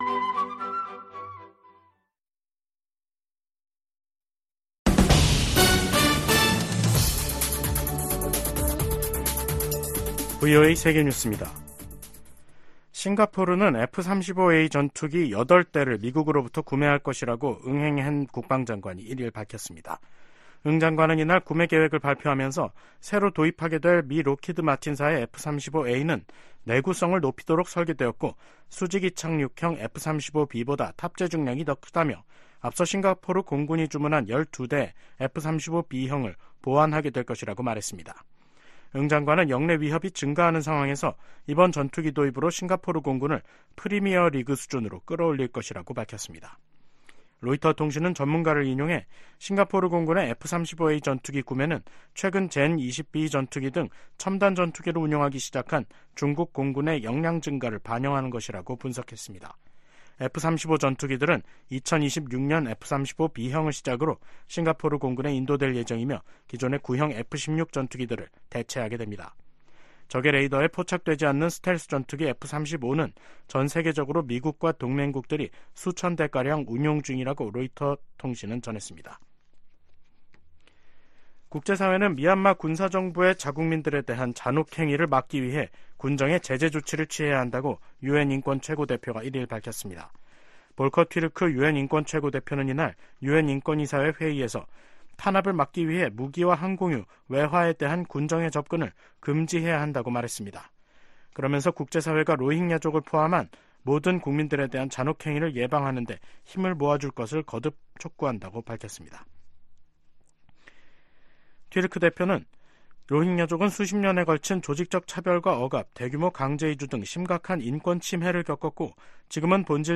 VOA 한국어 간판 뉴스 프로그램 '뉴스 투데이', 2024년 3월 1일 3부 방송입니다. 북한이 미국의 우주 체계를 위협할 사이버전과 전자전 역량을 갖췄다고 미군 고위 당국자들이 평가했습니다. 윤석열 한국 대통령은 제105주년 3.1절 기념식에서 북한에 자유와 인권을 확장하는 게 통일이라고 말했습니다. 커트 캠벨 미 국무부 부장관이 방미 중인 조태열 한국 외교부 장관을 만나 북한 문제와 북-러시아 무기거래, 미한동맹 강화 등을 논의했습니다.